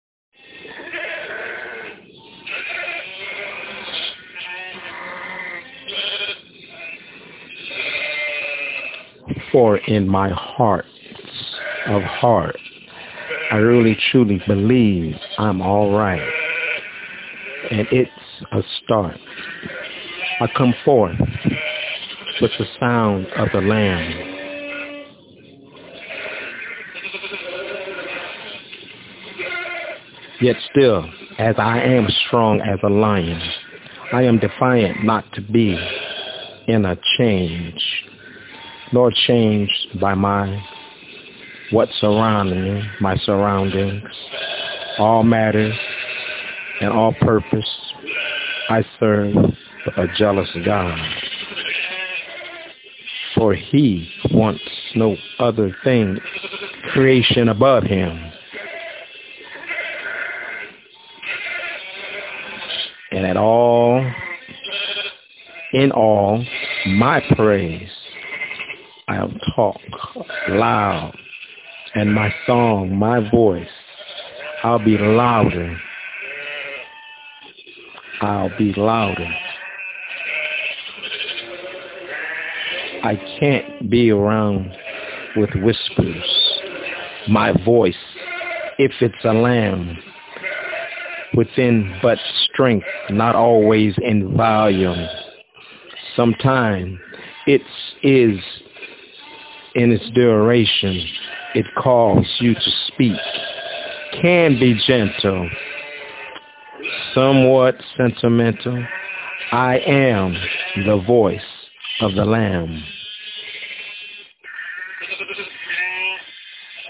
Voice of the Lamb- My Spokenword